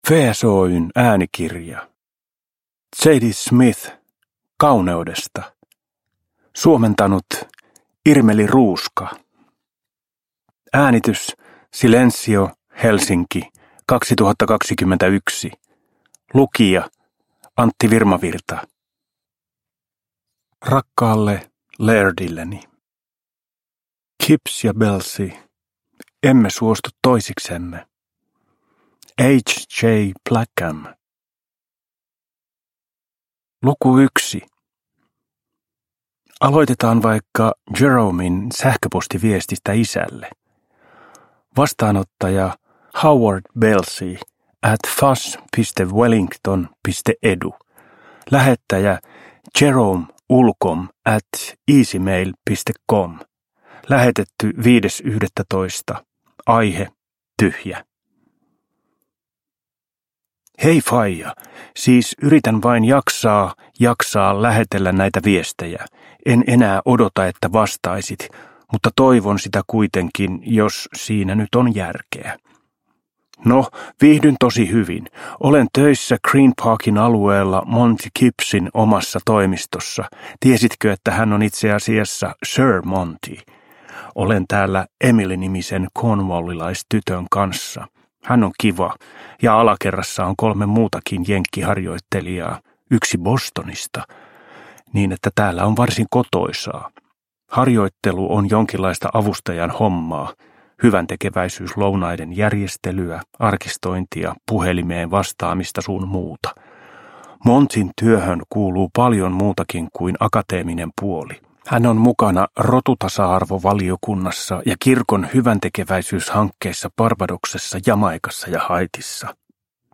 Kauneudesta – Ljudbok – Laddas ner